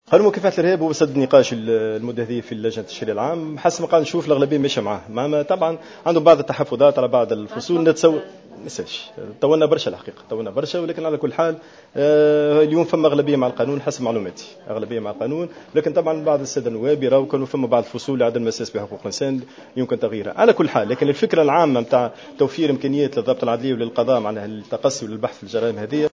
قال الأمين العام لحزب التيار الديمقراطي محمد عبو في تصريح لجوهرة "اف ام" خلال ندوة صحفية عقدت بالعاصمة مساء الخميس 3 جويلية 2014 إن لجنة التشريع العام صلب المجلس التأسيسي بصدد مناقشة القانون المتعلق بالإرهاب حاليا مؤكدا أن اغلبية النواب مع تمرير هذا القانون رغم وجود تحفظات على بعض فصوله .